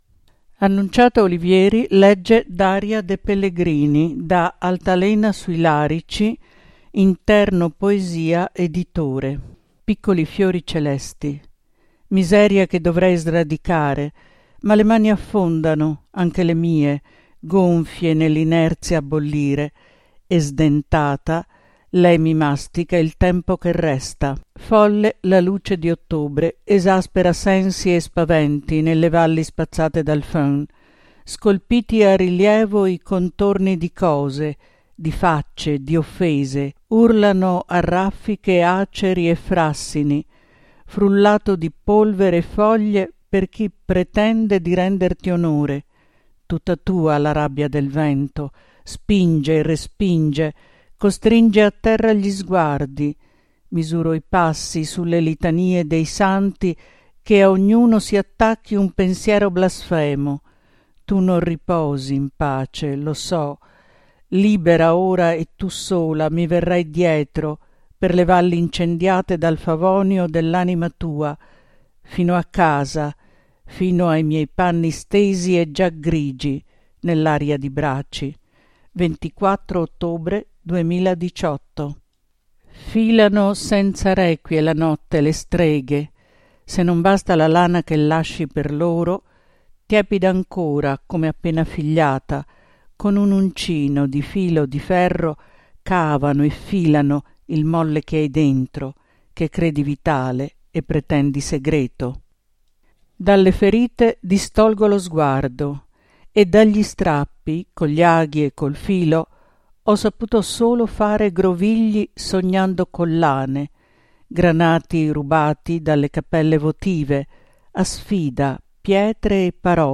Audiolettura